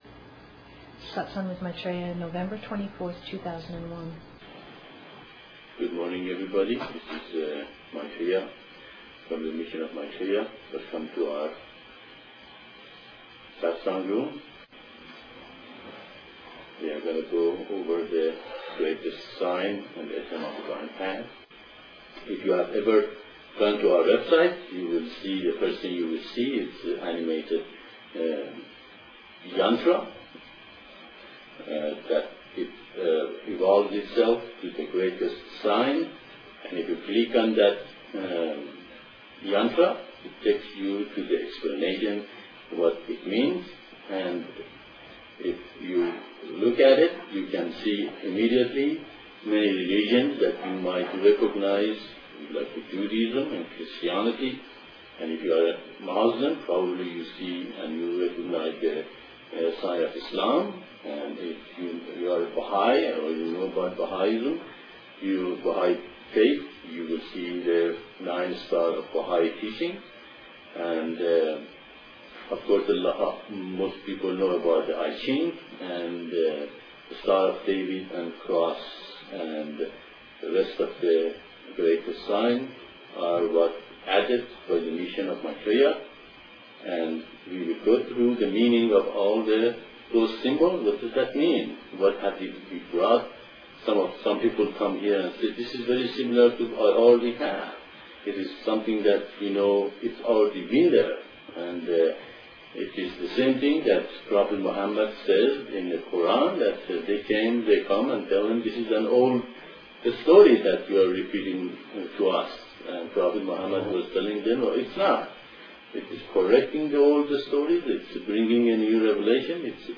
Satsang (Discourse) 11/24/01